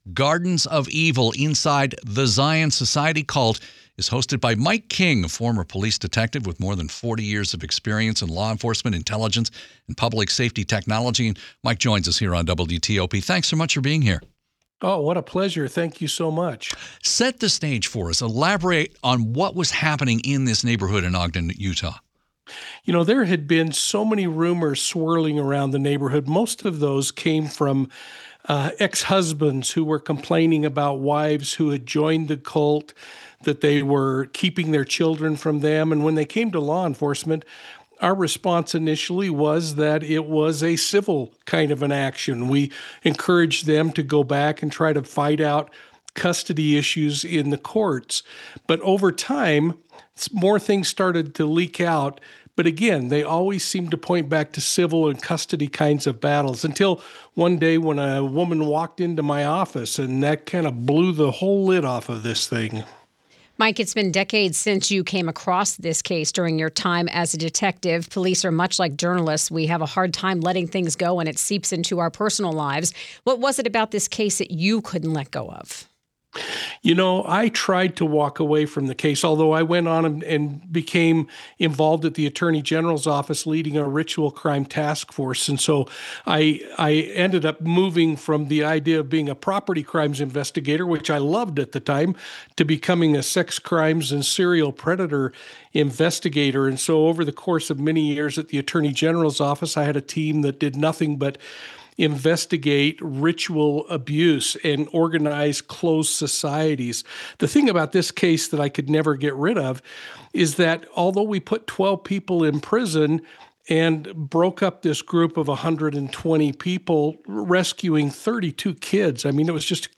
american-nightmares-interview.mp3